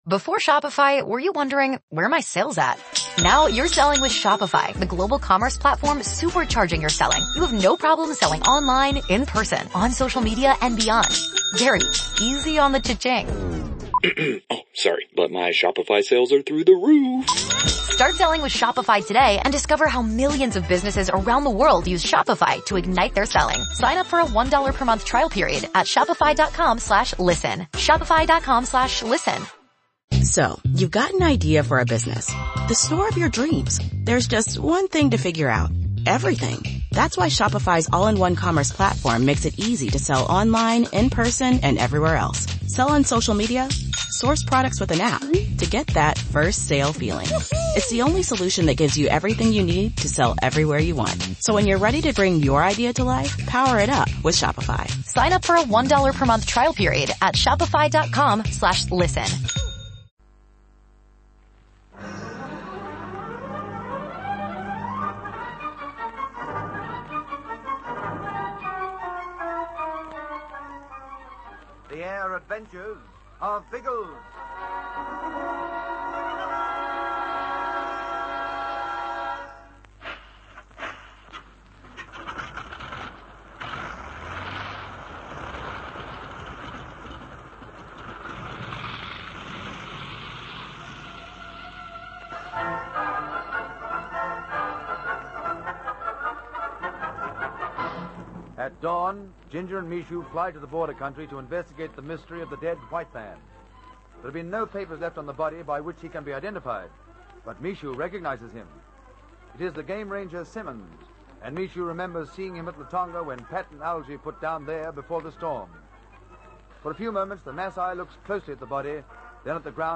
The Air Adventures of Biggles was a popular radio show that ran for almost a decade in Australia, from 1945 to 1954.
Biggles and his trusty companions, Ginger Hebblethwaite and Algy Lacey, soared through the skies in a variety of aircraft, from biplanes to jet fighters, taking on villains, rescuing damsels in distress, and generally having a whale of a time. The show was known for its exciting sound